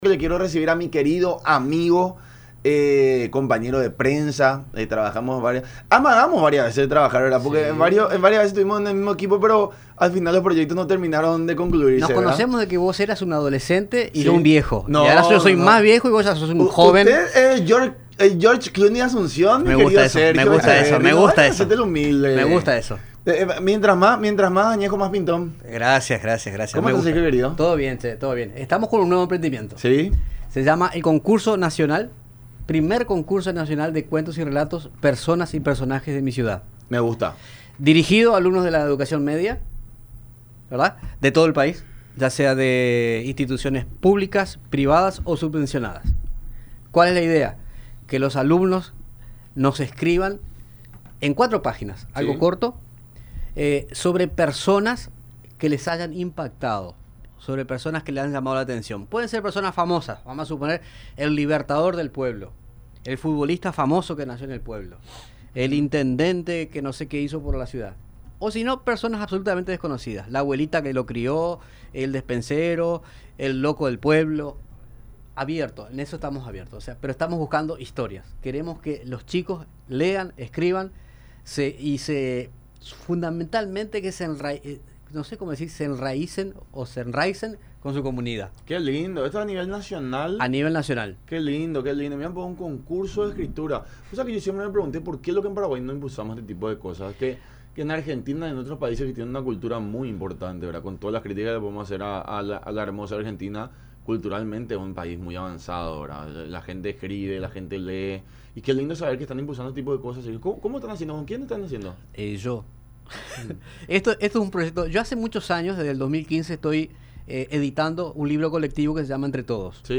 en entrevista con “La Unión Hace La Fuerza” por radio La Unión y Unión Tv.